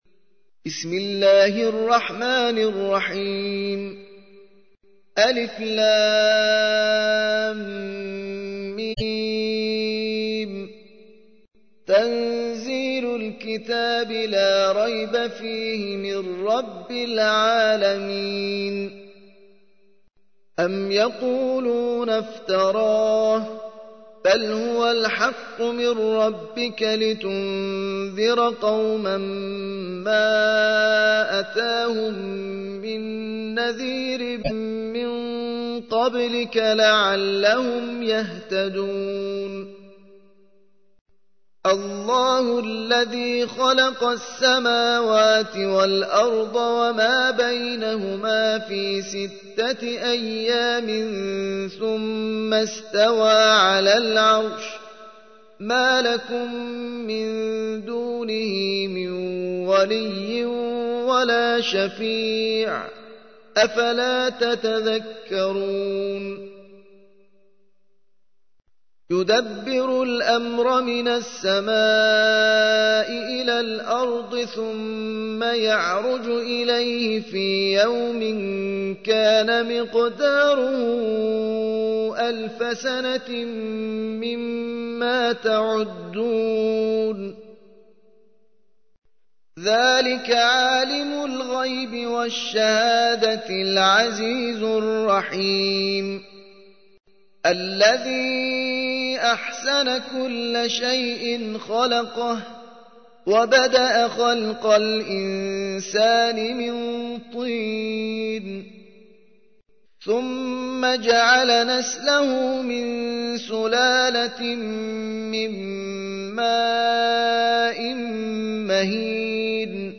32. سورة السجدة / القارئ